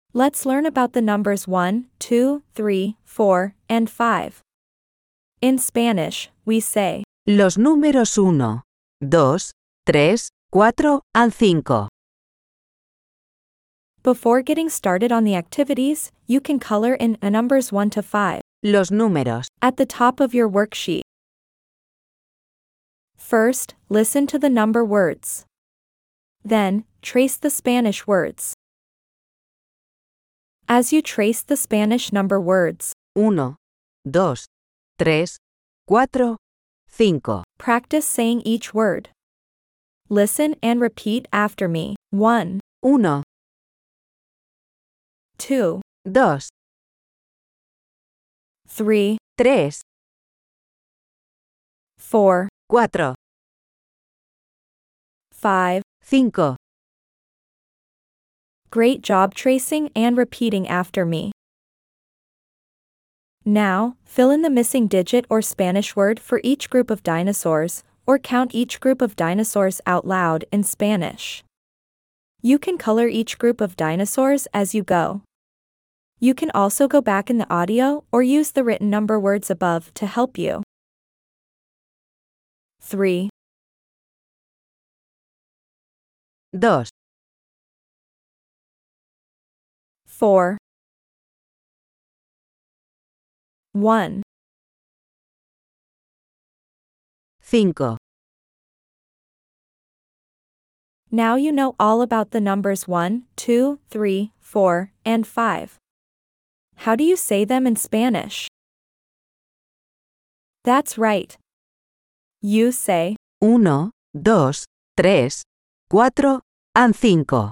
If you haven’t received your first box yet, you’ll find a free introduction to number words and an accompanying audio file for pronunciation at the end of this post.